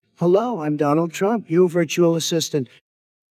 hello-virtual-assistant.wav